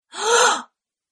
Woman Astonished Gasp